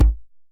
DJEM.HIT07.wav